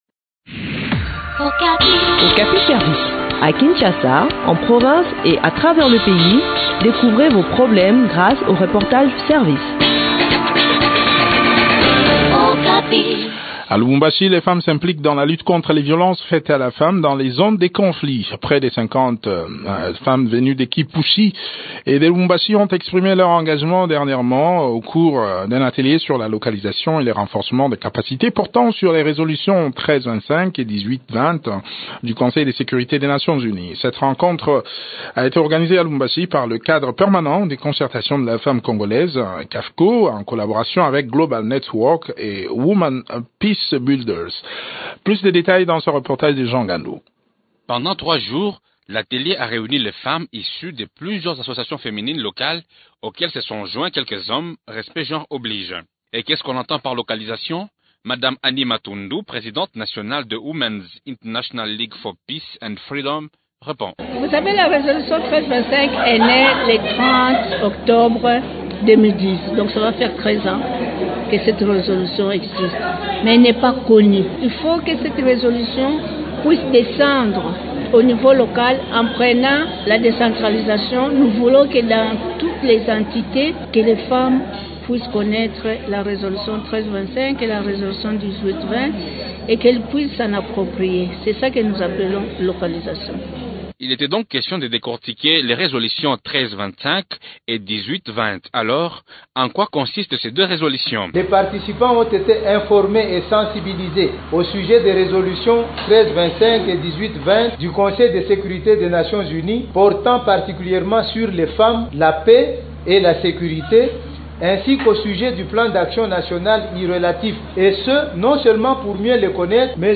Le point sur l’organisation de ce séminaire dans cet entretien